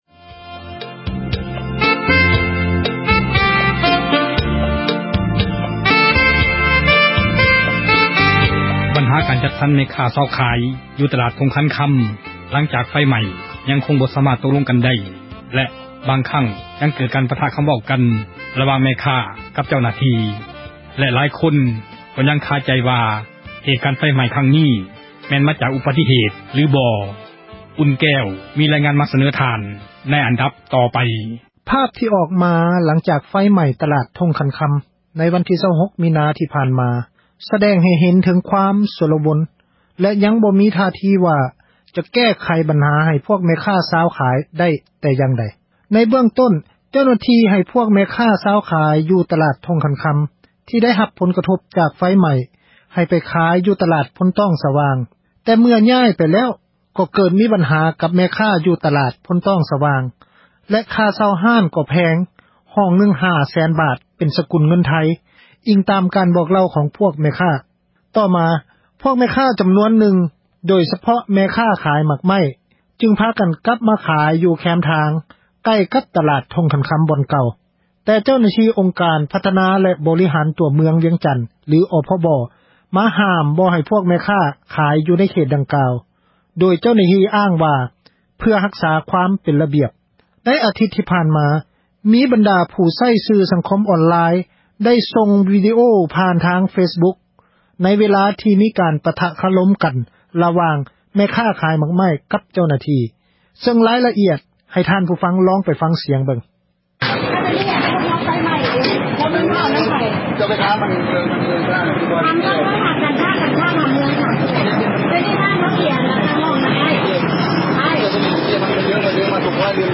ຣາຍຣະອຽດ ໃຫ້ທ່ານ ຜູ້ຟັງລອງໄປ ຟັງສຽງເບິ່ງ: ... ສຽງແມ່ຄ້າ...